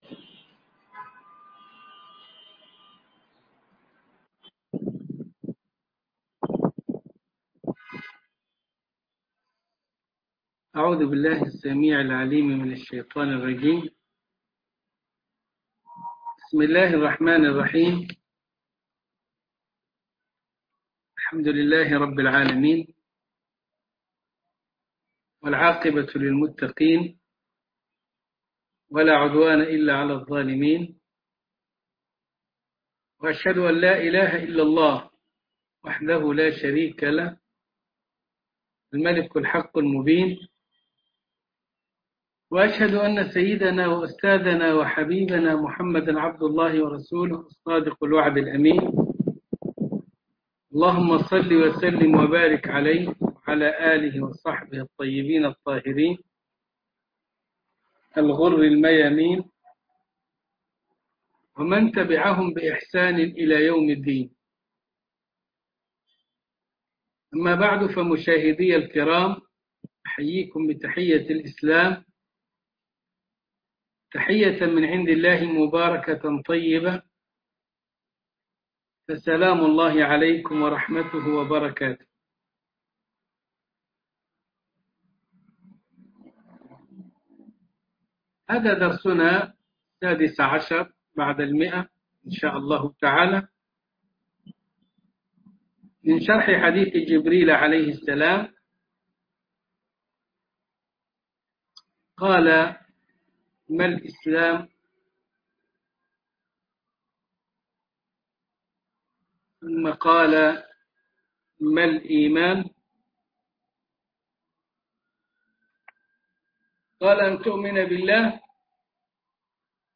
عنوان المادة الدرس ( 116 ) ( شرح حديث جبريل عن الاسلام والايمان والاحسان) تاريخ التحميل الأحد 28 فبراير 2021 مـ حجم المادة 30.55 ميجا بايت عدد الزيارات 286 زيارة عدد مرات الحفظ 147 مرة إستماع المادة حفظ المادة اضف تعليقك أرسل لصديق